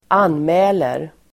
Uttal: [²'an:mä:ler]